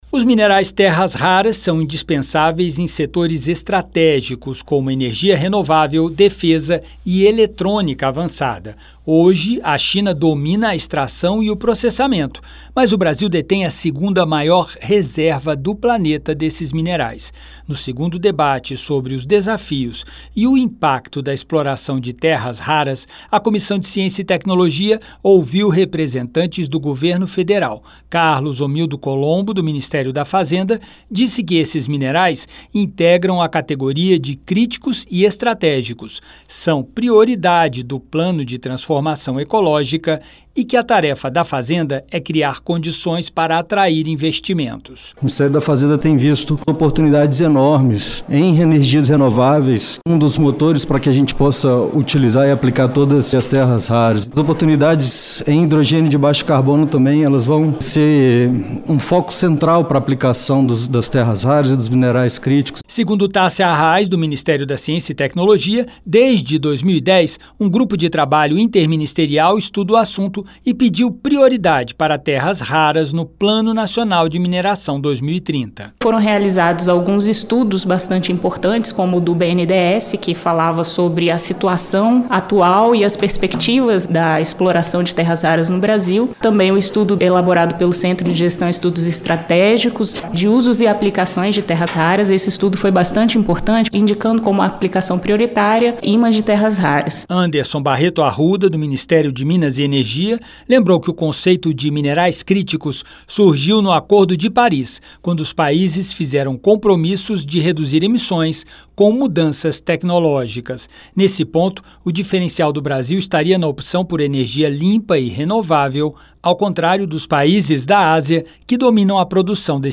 A Comissão de Ciência e Tecnologia (CCT) promoveu audiência pública nesta quarta-feira (17) para discutir os desafios da exploração e do beneficiamento das chamadas terras-raras — minerais essenciais para setores estratégicos como energia renovável, defesa e eletrônica avançada. Representantes do governo e especialistas destacaram a importância do tema para a transição energética e a necessidade de medidas do Congresso para estimular o setor.